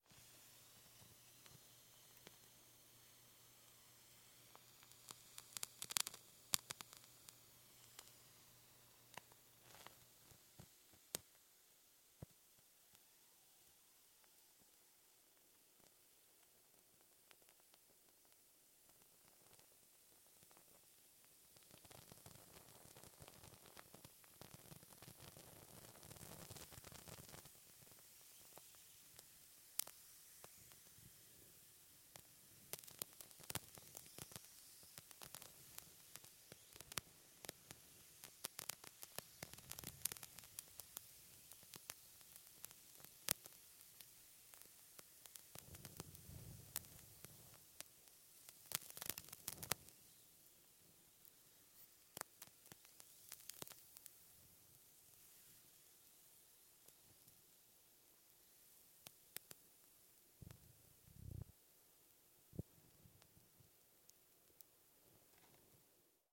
На этой странице собраны звуки горящих свечей – от тихого потрескивания до ровного горения.
Тихий шорох горящей свечи